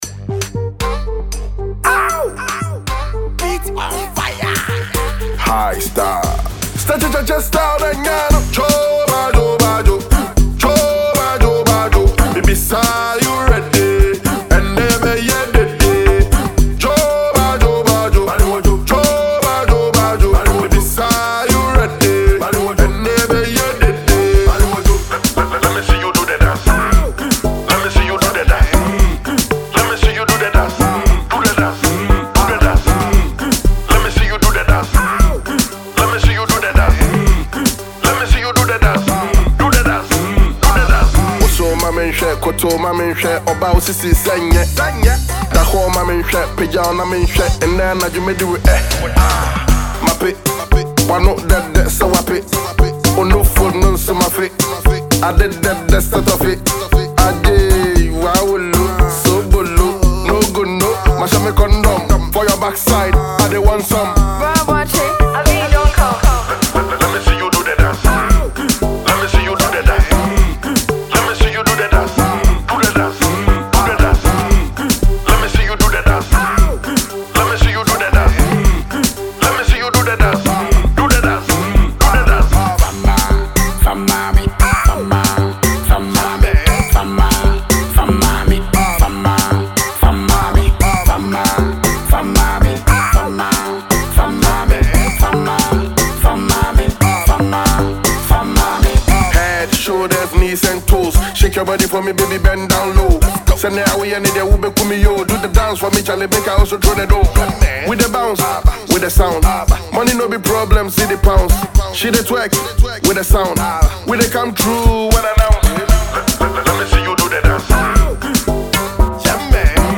a lively and dance-ready amapiano-like anthem